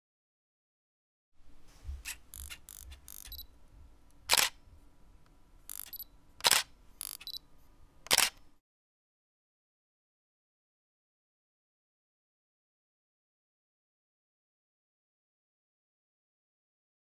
دانلود آهنگ عکس گرفتن دوربین عکاسی 1 از افکت صوتی اشیاء
جلوه های صوتی
دانلود صدای عکس گرفتن دوربین عکاسی 1 از ساعد نیوز با لینک مستقیم و کیفیت بالا
برچسب: دانلود آهنگ های افکت صوتی اشیاء دانلود آلبوم صدای زوم و عکس گرفتن دوربین عکاسی از افکت صوتی اشیاء